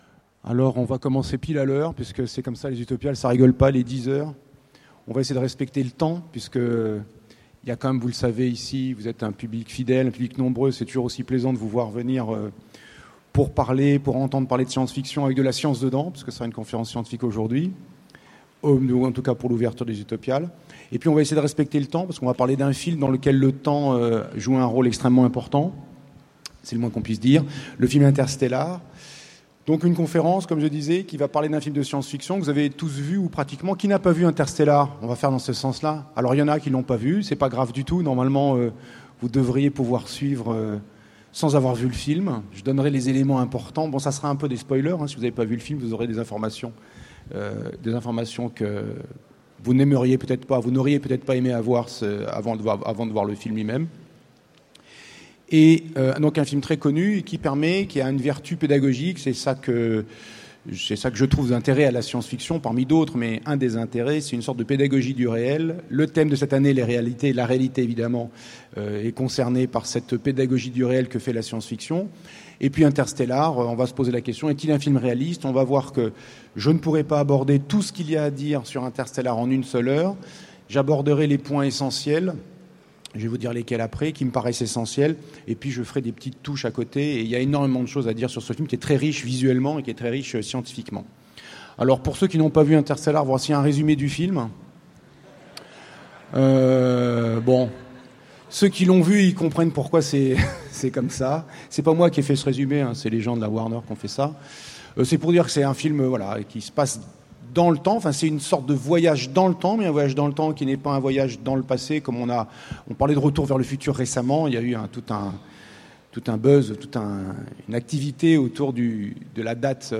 Utopiales 2015 : Conférence Interstellar est-il un film réaliste ?
- le 31/10/2017 Partager Commenter Utopiales 2015 : Conférence Interstellar est-il un film réaliste ?